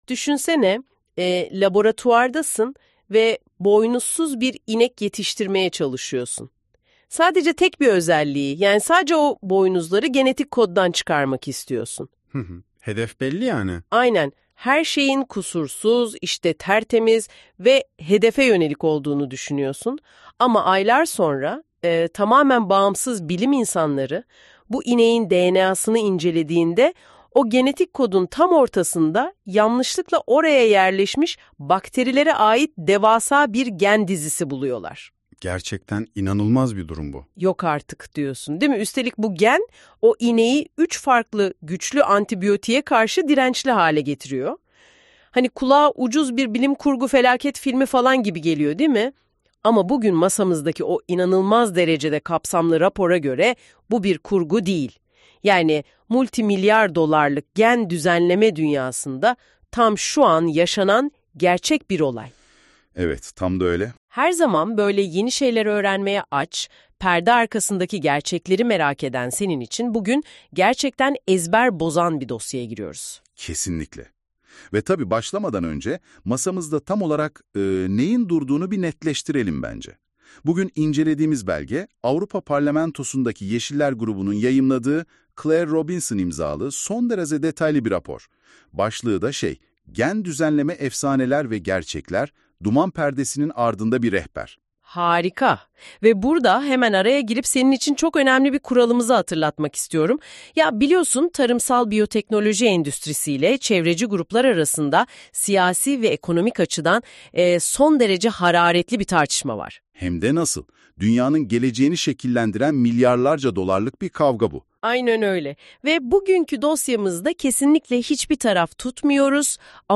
Sesli Kitaplar